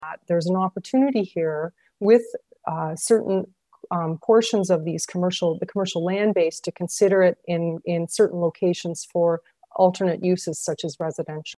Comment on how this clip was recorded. At Monday night’s meeting of the city’s Planning Committee